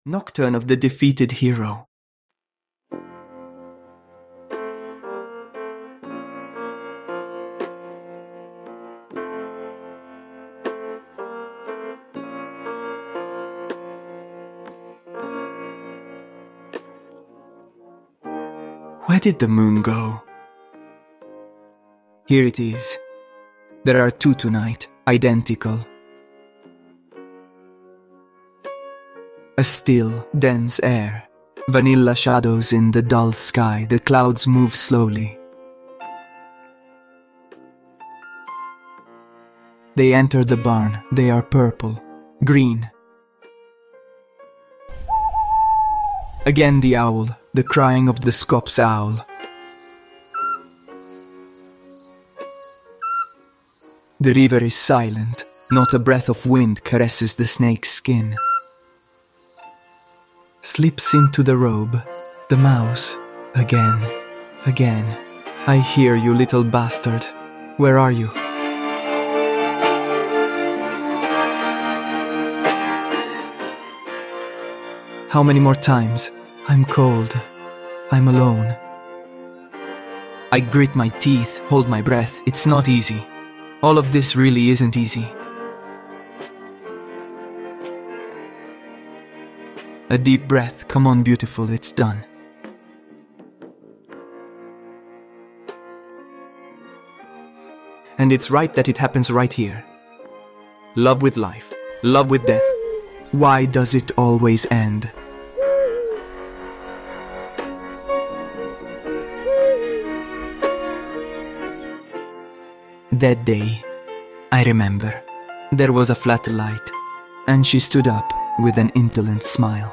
This can be considered a page torn from Emmanuel's diary: it is a short delirious monologue, a stream of consciousness in which the boy freely lets his thoughts flow on the wave of depression and unconsciousness, while he is in the barn where he used to go with Antonia.
The soundtrack consists of an instrumental version of Lou Reed's "Perfect Day" (tribute to Trainspotting).